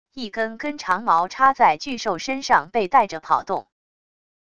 一根根长矛插在巨兽身上被带着跑动wav音频